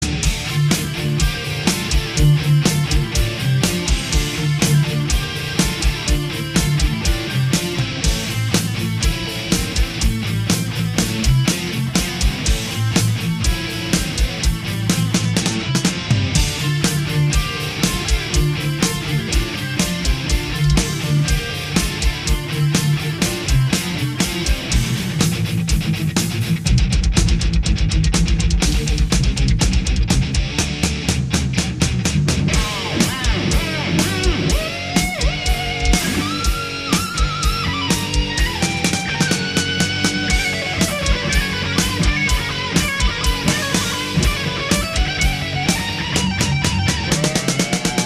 guitar solos